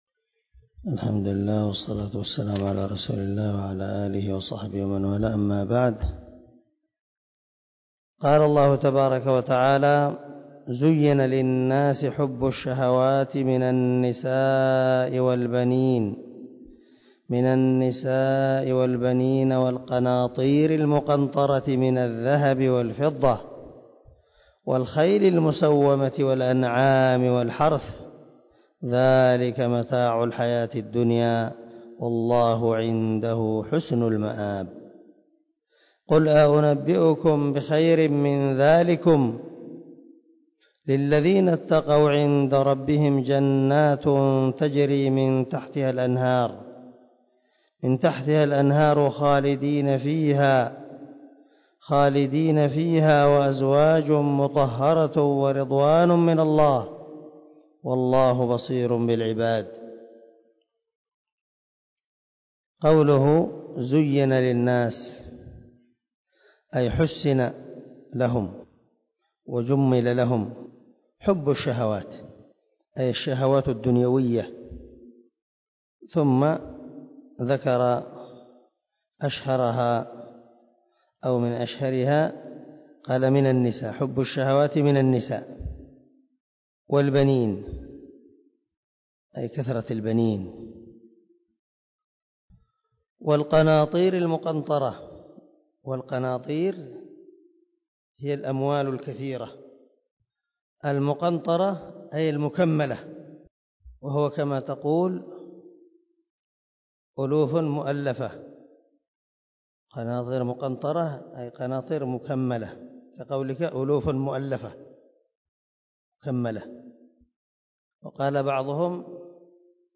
159الدرس 4 تفسير آية ( 14 – 15 ) من سورة آل عمران من تفسير القران الكريم مع قراءة لتفسير السعدي